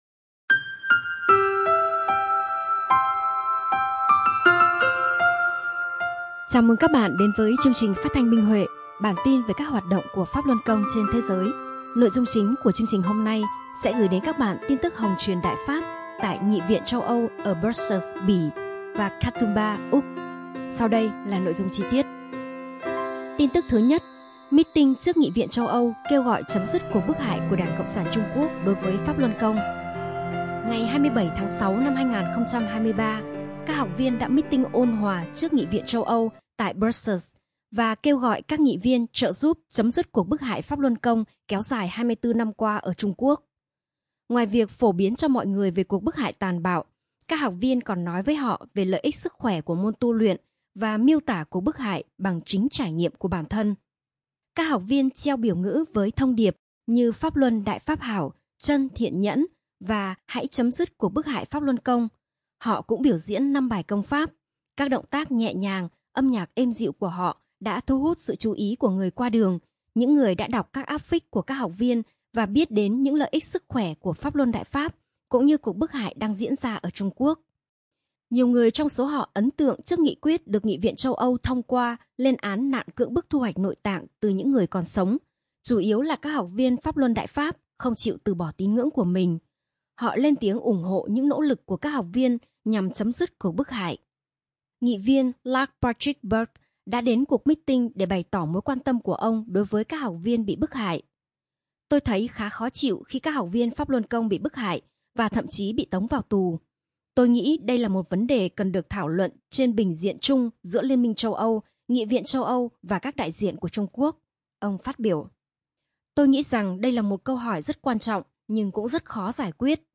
Chương trình phát thanh số 43: Tin tức Pháp Luân Đại Pháp trên thế giới – Ngày 7/7/2023